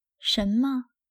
Shénme
シェンムァ